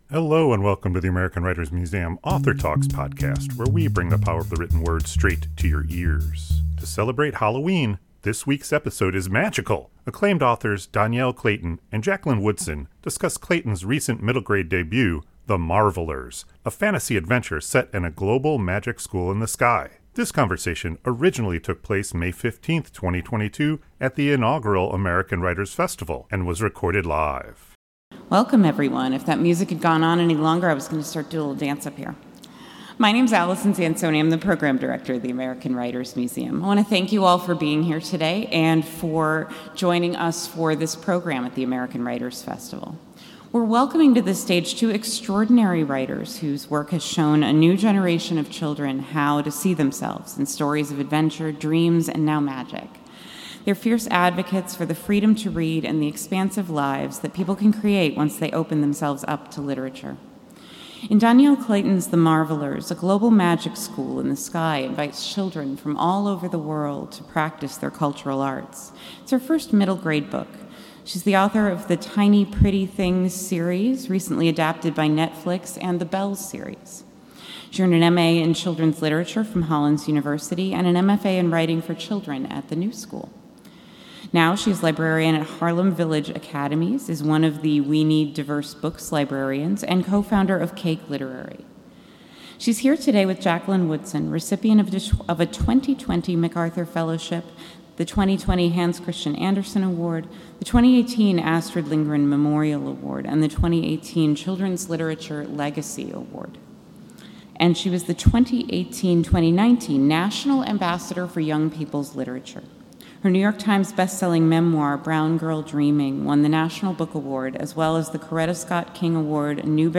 Live from the American Writers Festival, Dhonielle Clayton and Jacqueline Woodson discuss Clayton’s recent middle grade debut The Marvellers.
Acclaimed authors Dhonielle Clayton and Jacqueline Woodson discuss Clayton’s recent middle grade debut The Marvellers, a fantasy adventure set in a global magic school in the sky. This conversation originally took place May 15th, 2022 at the inaugural American Writers Festival and was recorded live.